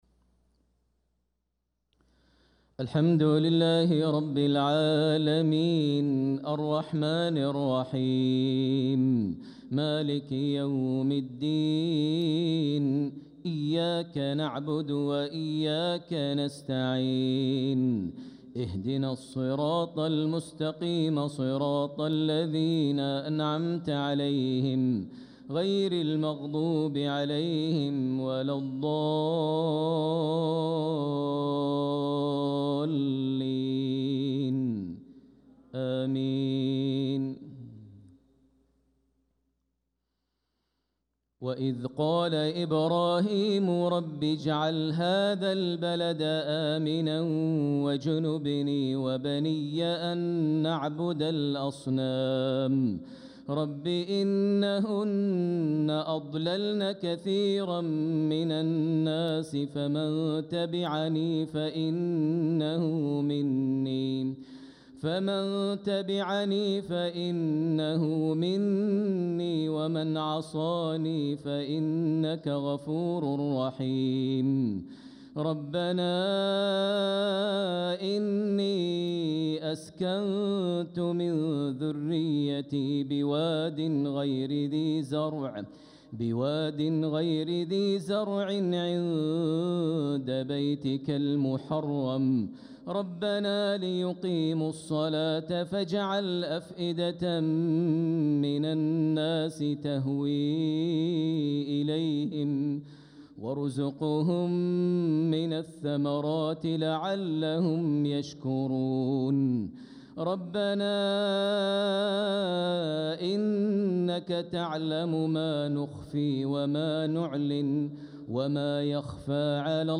صلاة العشاء للقارئ ماهر المعيقلي 24 ربيع الأول 1446 هـ
تِلَاوَات الْحَرَمَيْن .